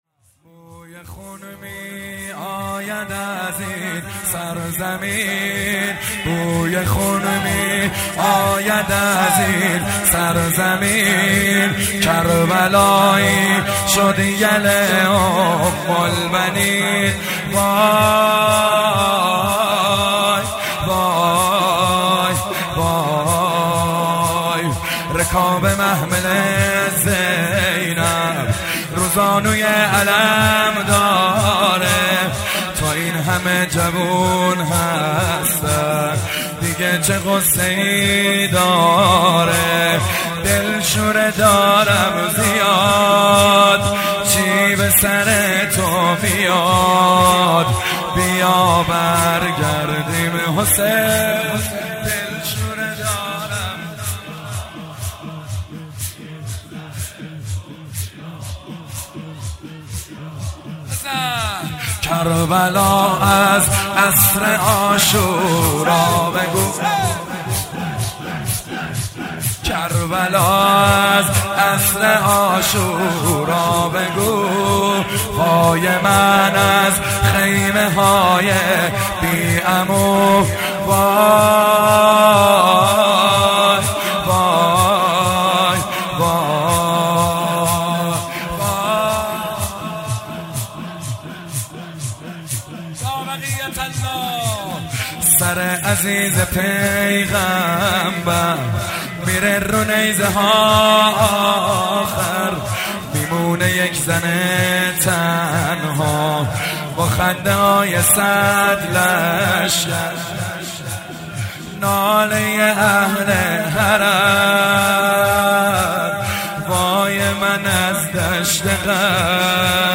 شور جدید